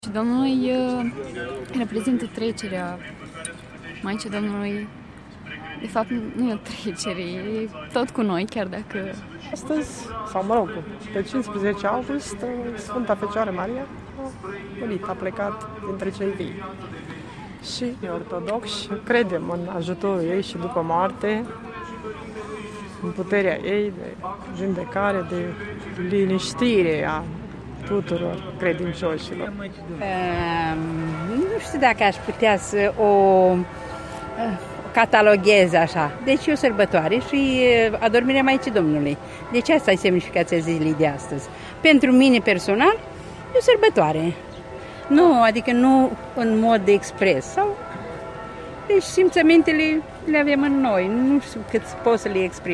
În Iași, credincioșii au participat la slujba Mitropoliei, s-au închinat și au aprins lumânări. Întrebați ce semnificație are această zi, unii au bâjbâit un răspuns simplu iar alții au explicat înțelesul  spiritual al sărăbătorii.